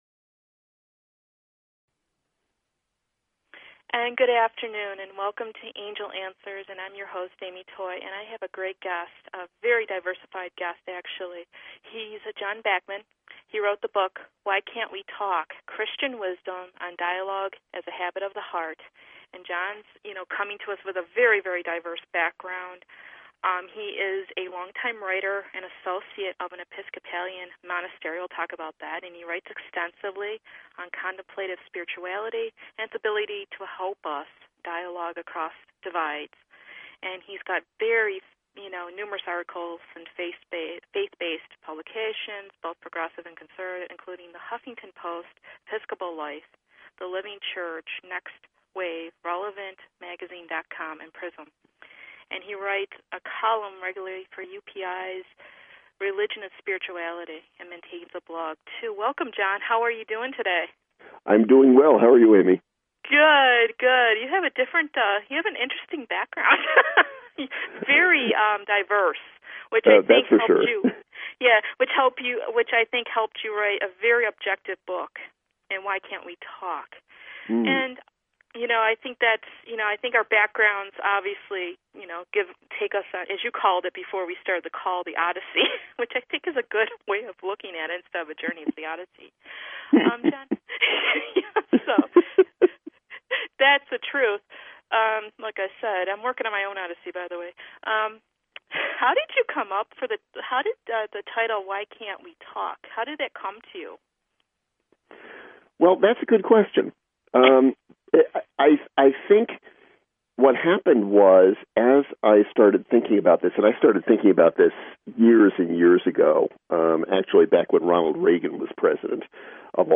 Talk Show Episode, Audio Podcast, Angel_Answers and Courtesy of BBS Radio on , show guests , about , categorized as